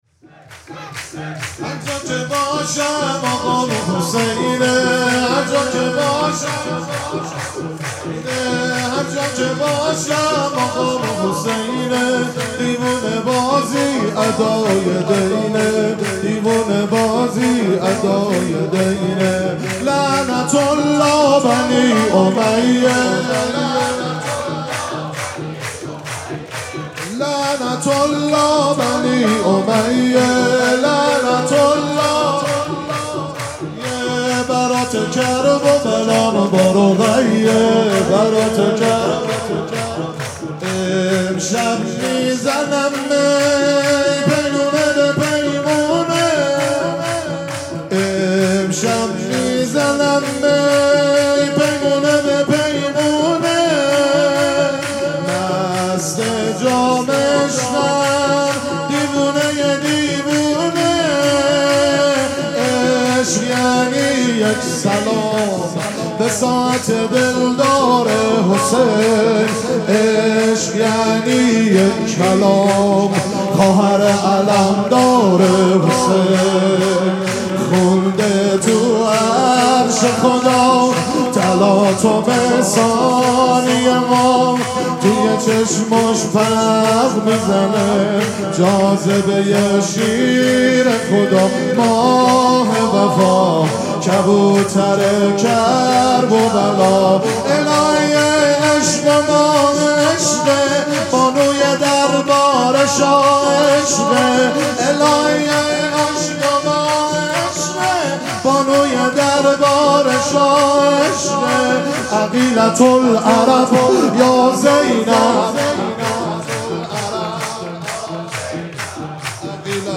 مراسم جشن ولادت حضرت زینب سلام‌الله‌علیها
شور
مداح